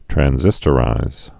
(trăn-zĭstə-rīz, -sĭs-)